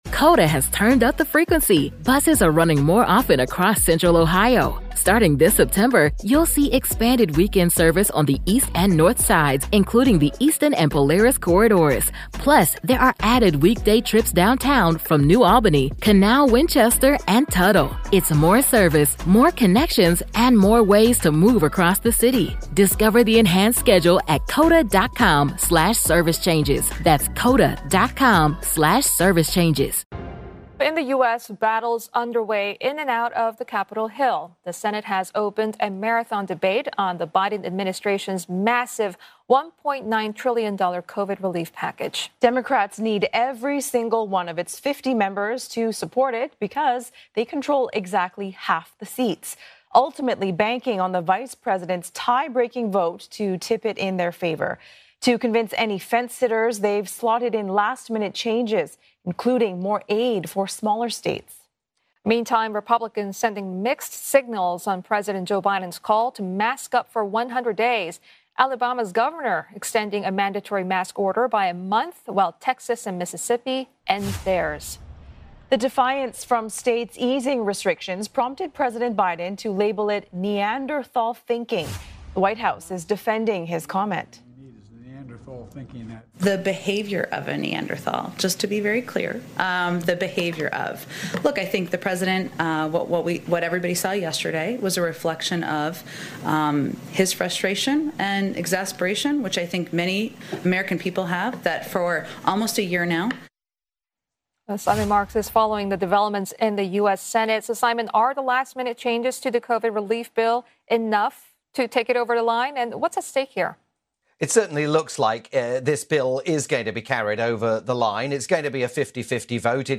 live report on the ludicrous scenes sparked in the Senate by the intransigence of seditionist Republican Senator Ron Johnson of Wisconsin.